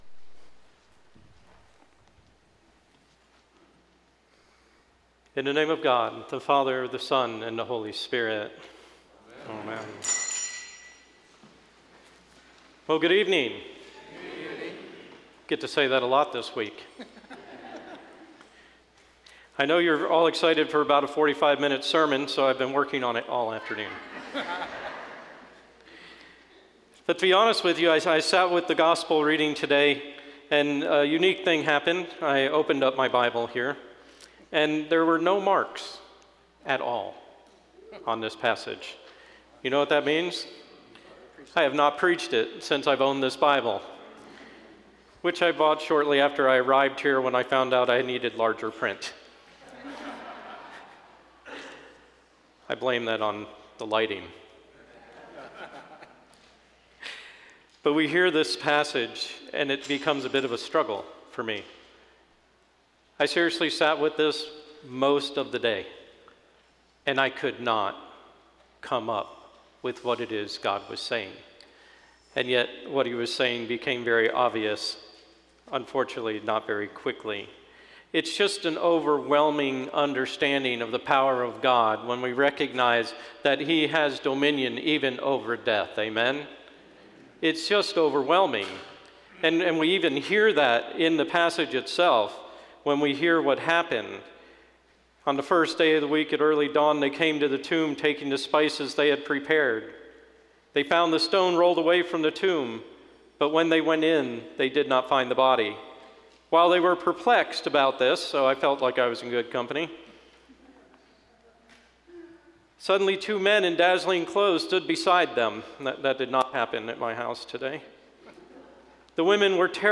Sermon 4/16/2022, Holy Saturday, Easter Vigil